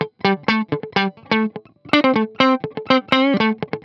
12 GuitarFunky Loop A.wav